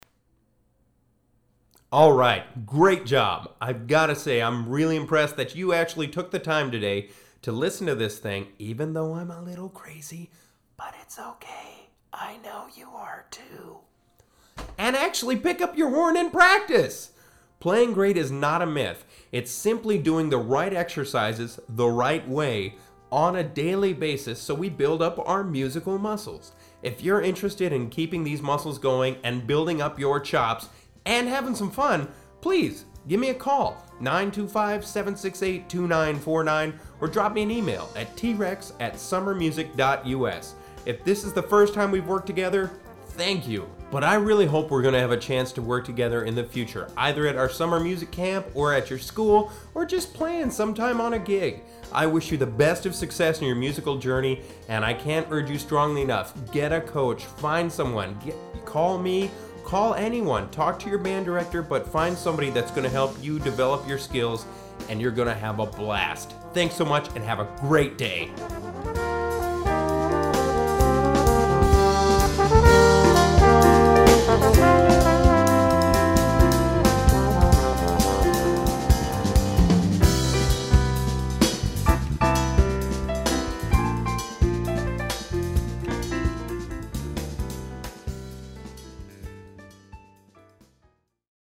Sample Lesson